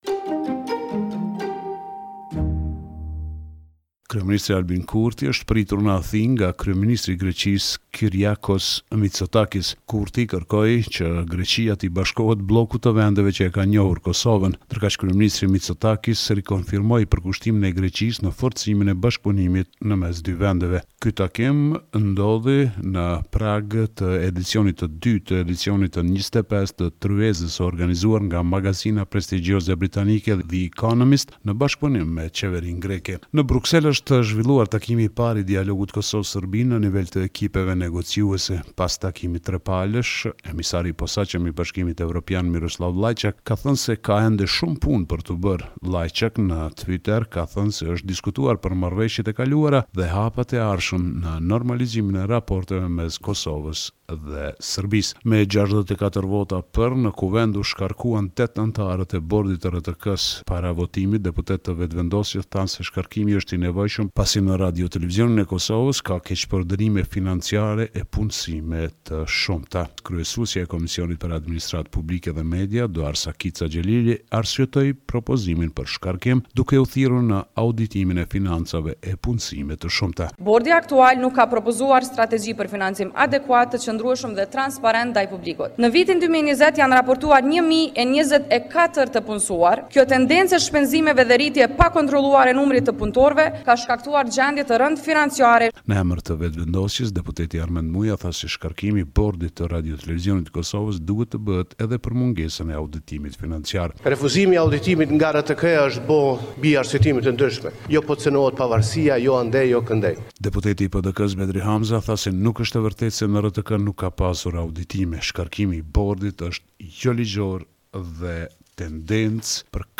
Raporti me te rejat me te fundit nga Kosova.